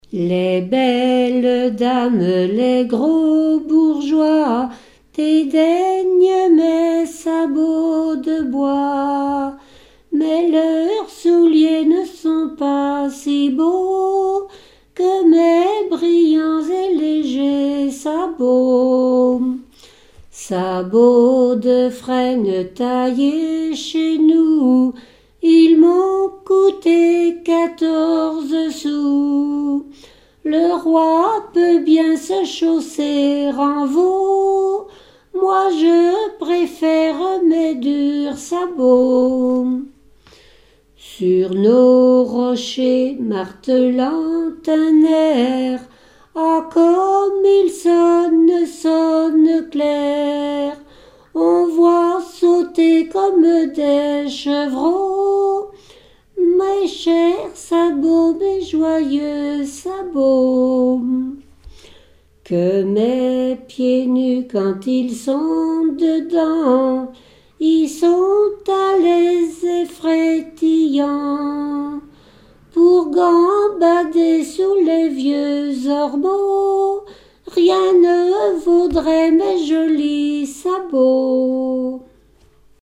Genre strophique
répetoire de chansons traditionnelles et populaires
Pièce musicale inédite